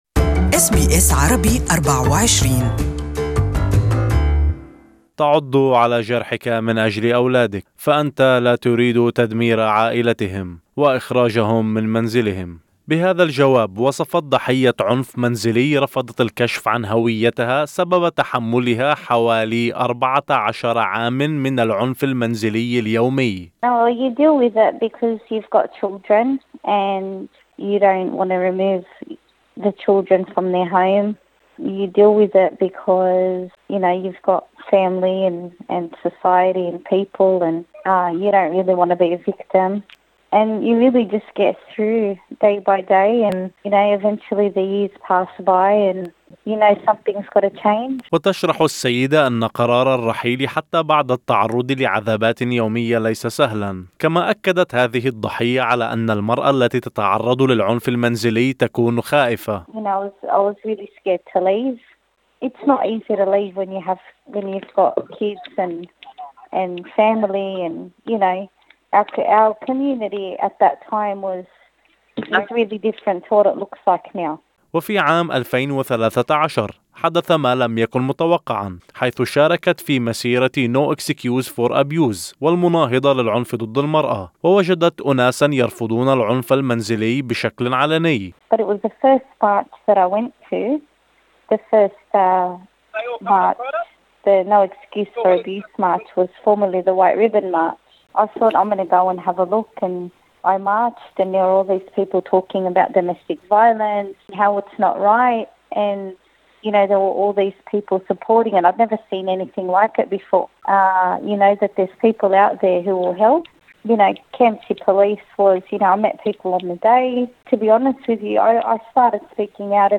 حفاظا على سرية السيدة، أجريت بعض التعديلات على صوتها.